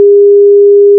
As you may recall, we started with a sine wave at 100Hz:
Now, 400Hz:
This is starting to get into painful territory for me (at least through headphones).
test_400Hz.wav